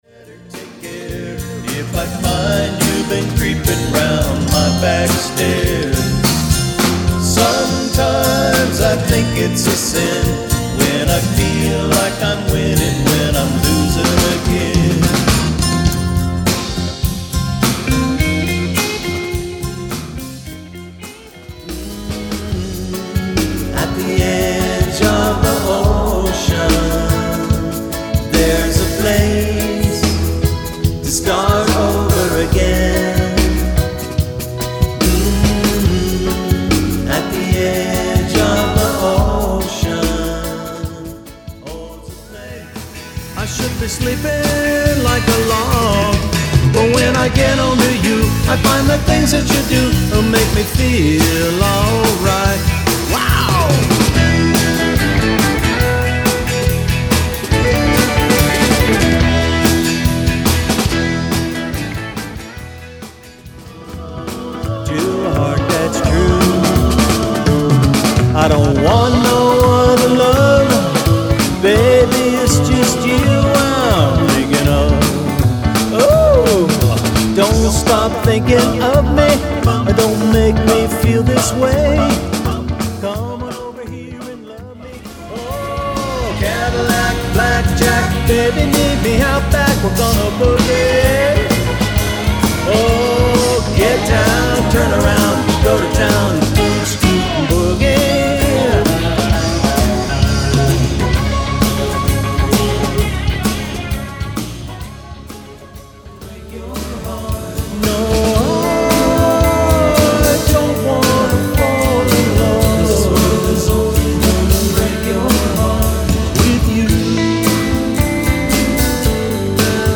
Short promotional selections from across the album.
Rock / Country / Pop sampler
• One-Man Band (drums, vocals, bass)